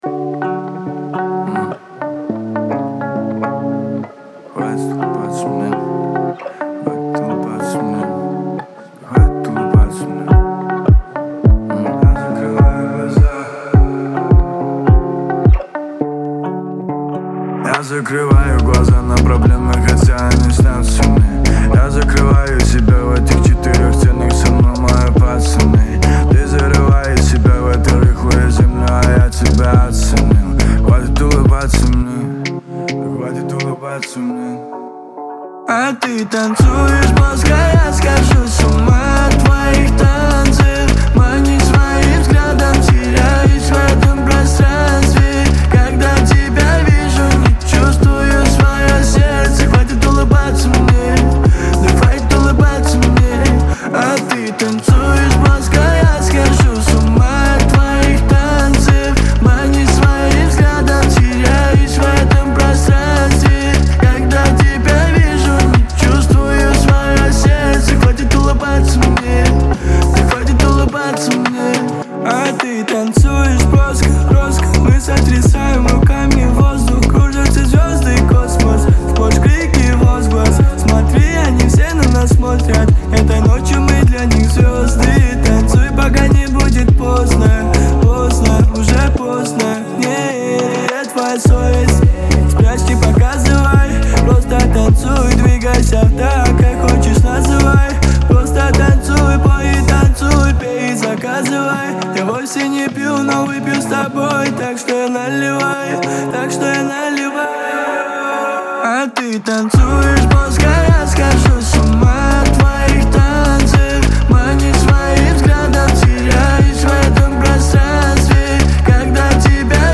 мощная композиция в жанре поп-рок